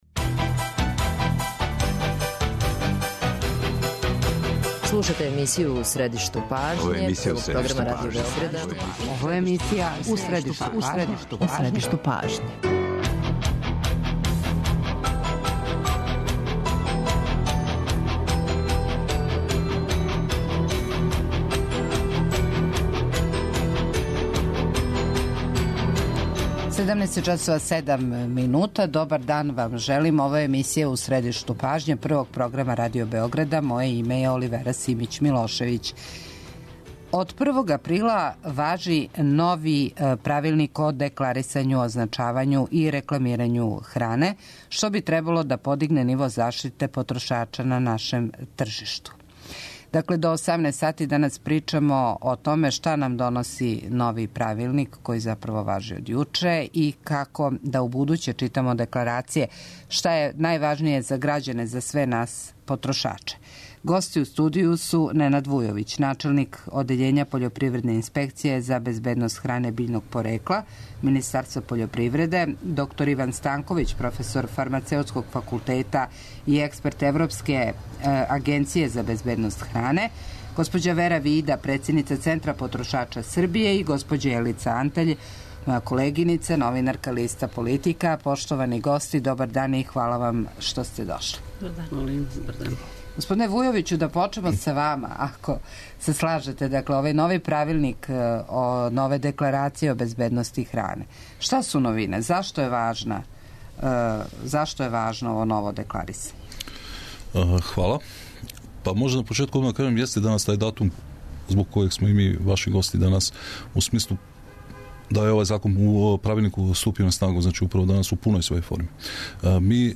доноси интервју